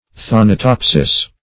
thanatopsis \than`a*top"sis\ (th[a^]n`[.a]*t[o^]p"s[i^]s), n.